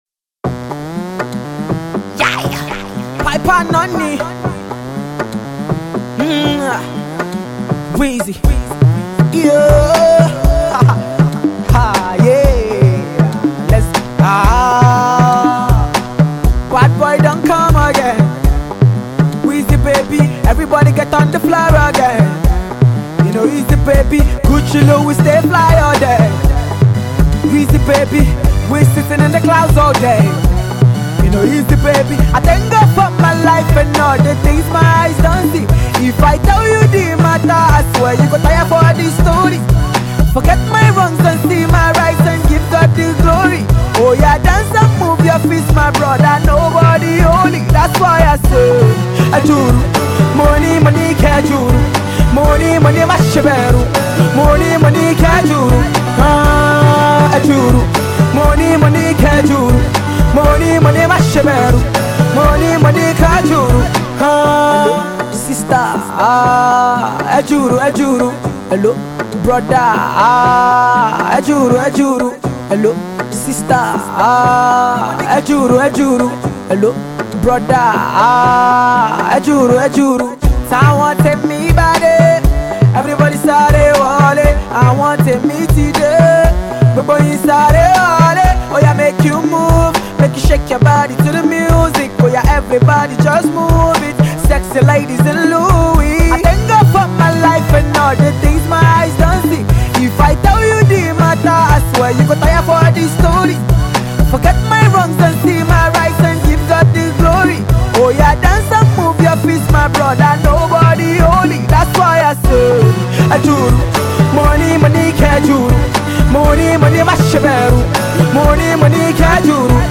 freestyles